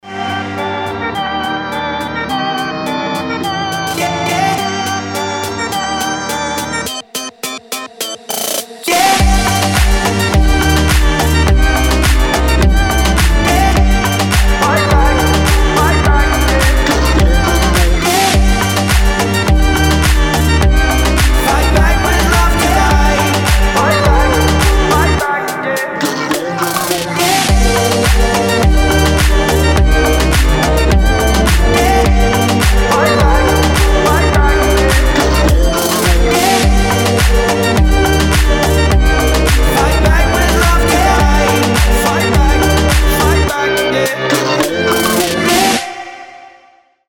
поп
dance